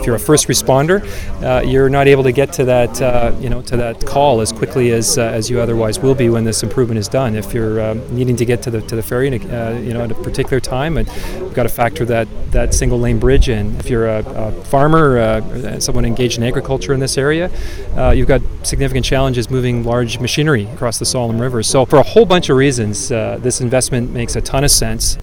Courtenay Mayor Larry Jangula was on hand for the news conference…